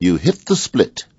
gutterball-3/Gutterball 3/Commentators/Jensen/jen_youhitthesplit.wav at 94cfafb36f1f1465e5e614fe6ed6f96a945e2483
jen_youhitthesplit.wav